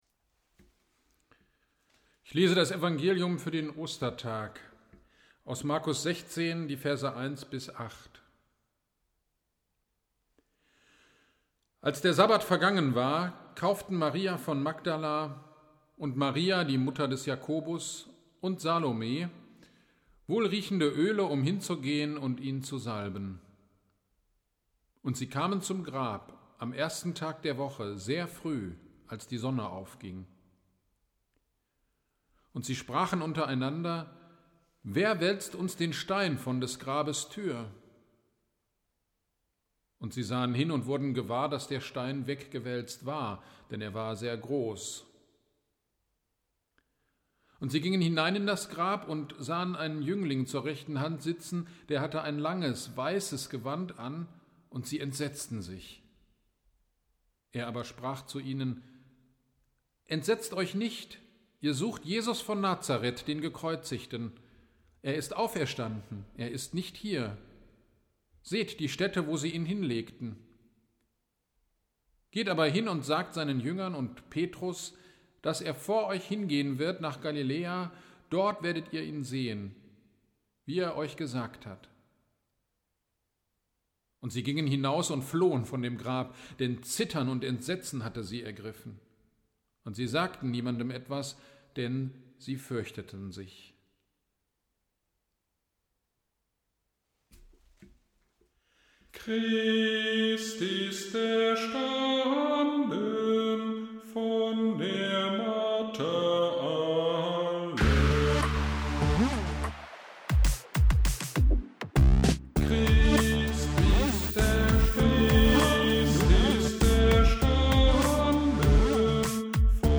Ostern 2022 Predigt zu Epheser 1.18-21